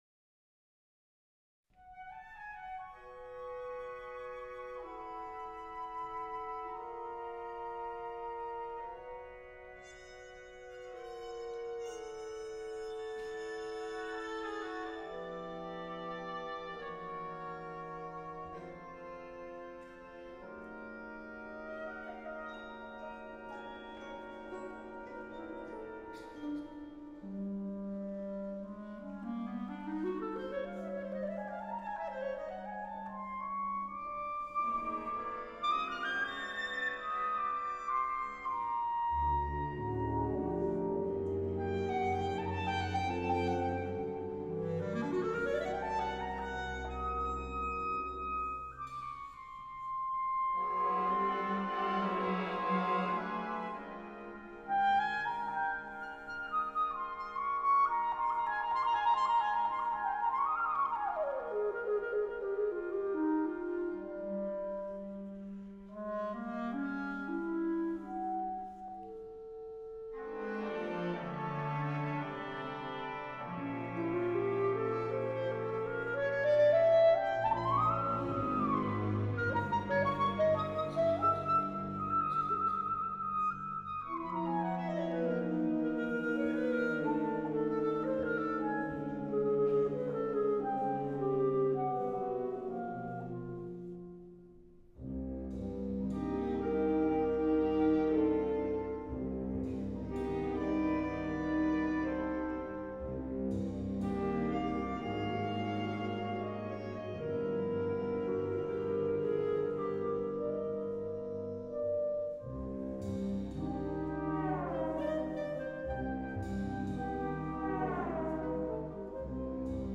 Please note: These samples are of varying quality.
Most were taken from live performances and are intended
for Clarinet and Orchestra